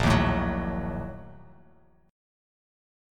A#M7sus4#5 chord